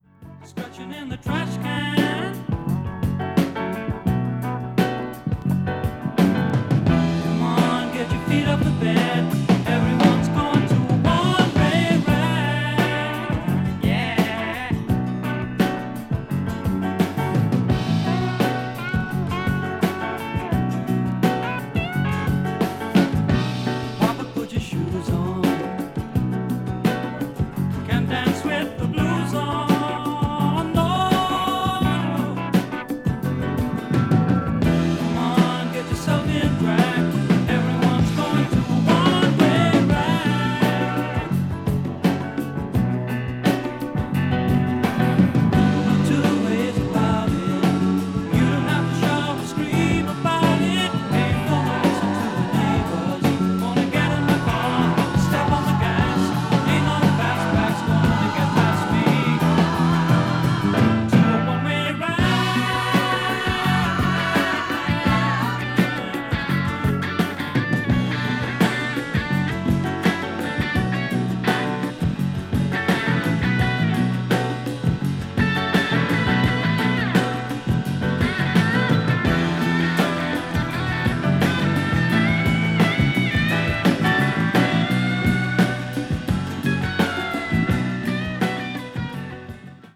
blue eyed soul   blues rock   british rock   country rock